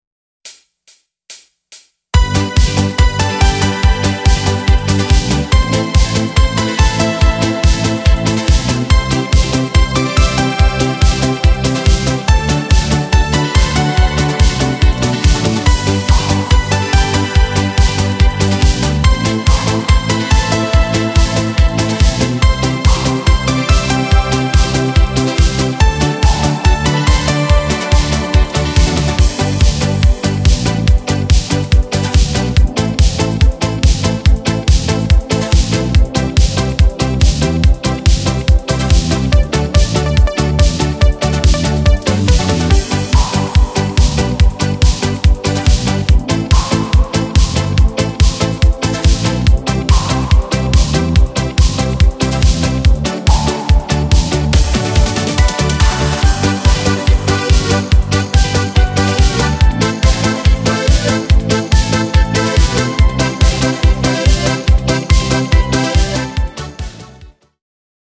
Kolejny utwór w odświeżonej, imprezowej wersji.
Disco Polo